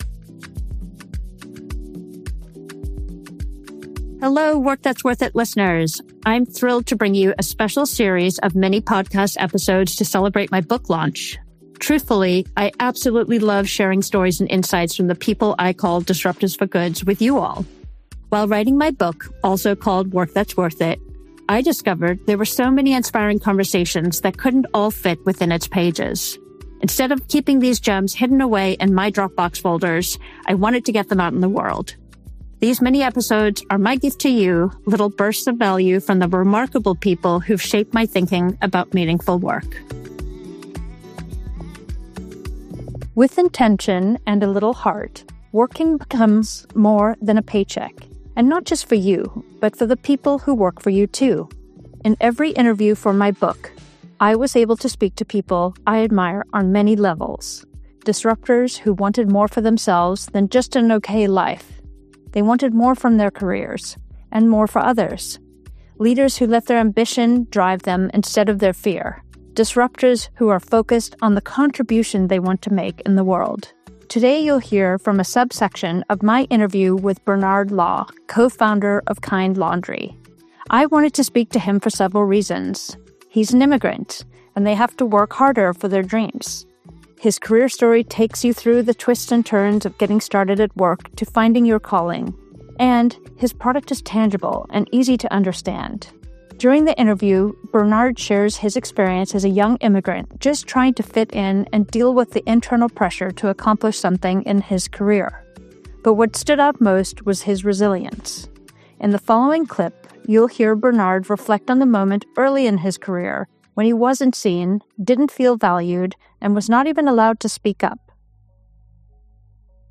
Join us for a conversation on resilience, true success, and leadership that actually feels good!